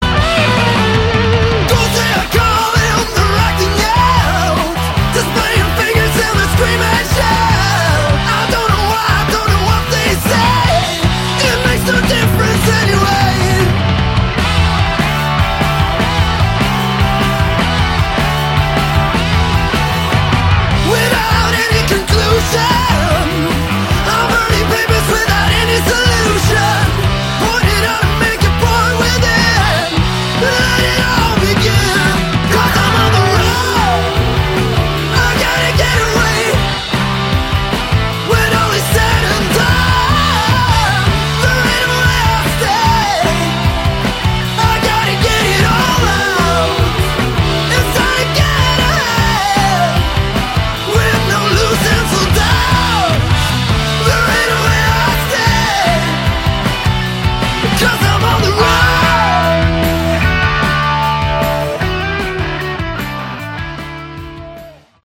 Category: Hard Rock
drums
guitars
vocals, bass